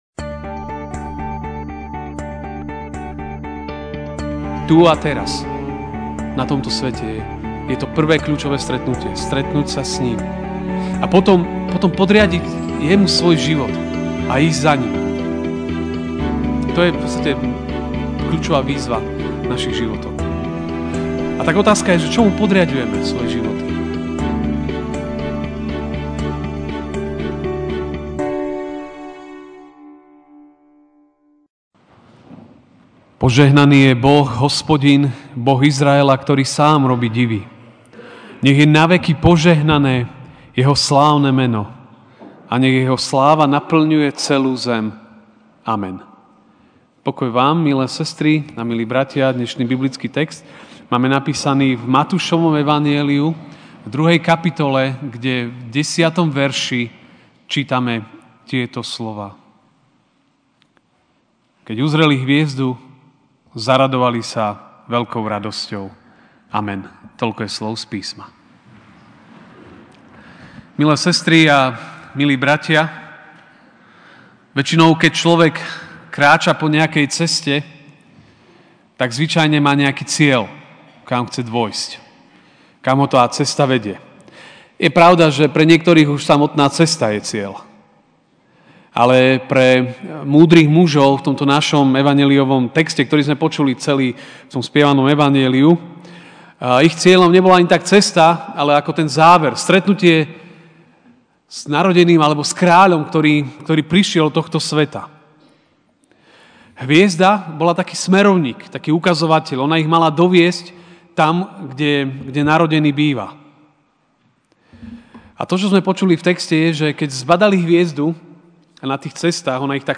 jan 06, 2019 To, čo nás privádza k Ježišovi MP3 SUBSCRIBE on iTunes(Podcast) Notes Sermons in this Series Ranná kázeň: To, čo nás privádza k Ježišovi (Mt 2, 10) Keď uzreli hviezdu, zaradovali sa veľkou radosťou.